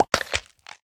Minecraft Version Minecraft Version latest Latest Release | Latest Snapshot latest / assets / minecraft / sounds / mob / turtle / egg / drop_egg2.ogg Compare With Compare With Latest Release | Latest Snapshot
drop_egg2.ogg